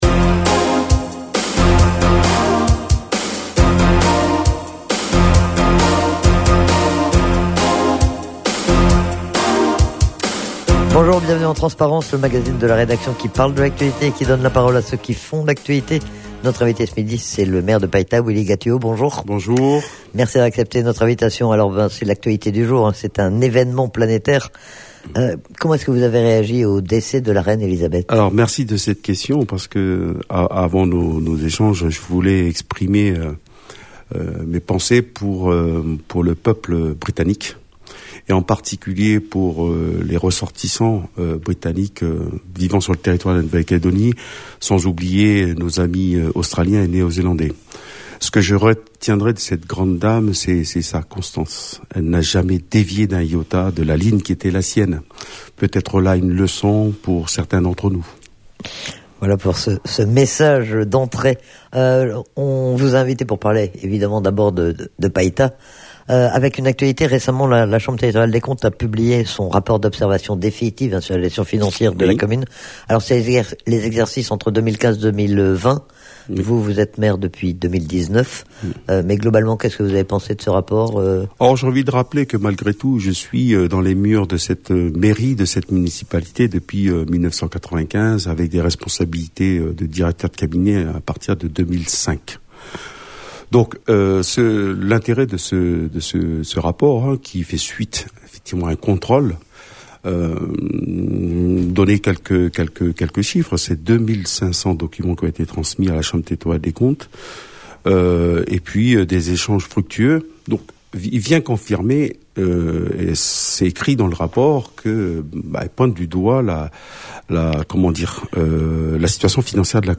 Il est interrogé sur la situation de sa commune et sur les problèmes auxquels il est confronté mais aussi, plus largement, sur l'actualité politique calédonienne.